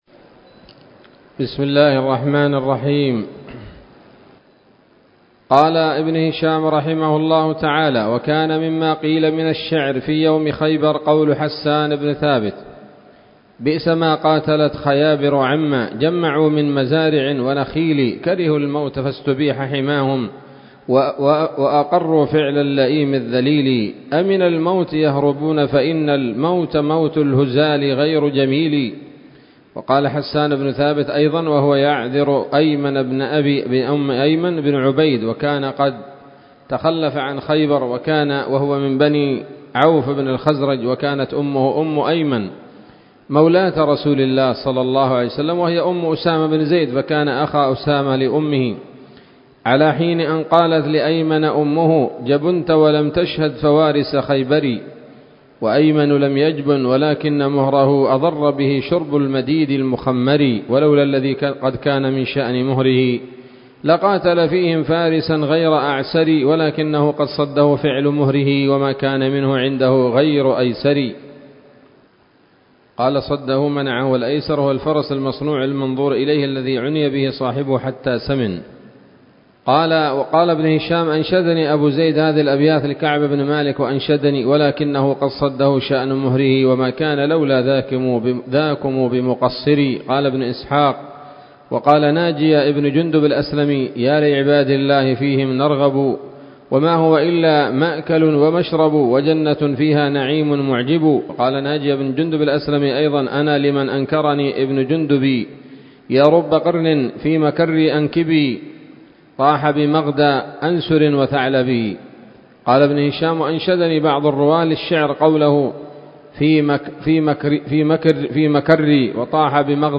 الدرس السابع والأربعون بعد المائتين من التعليق على كتاب السيرة النبوية لابن هشام